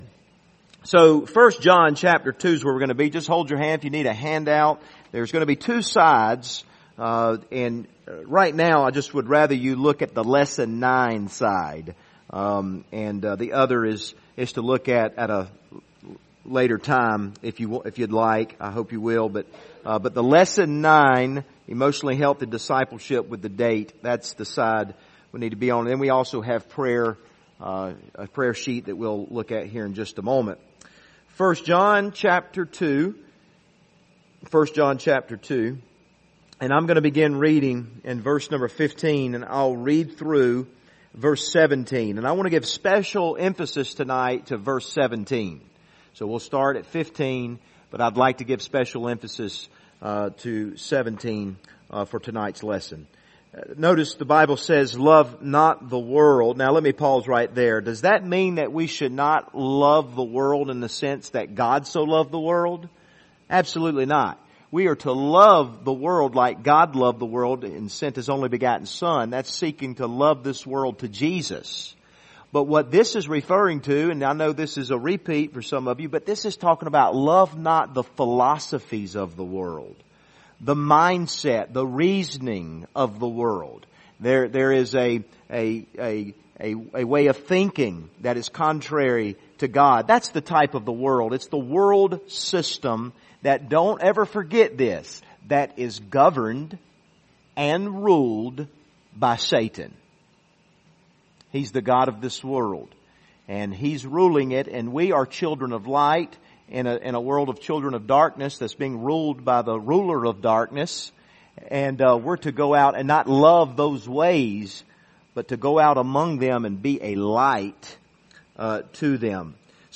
Passage: 1 John 2 Service Type: Wednesday Evening